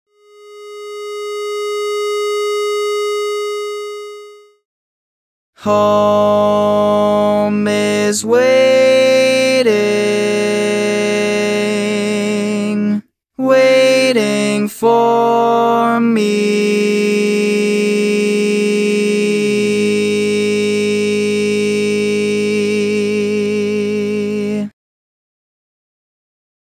Key written in: A♭ Major
Type: Barbershop